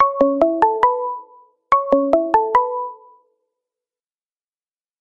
incoming-call.mp3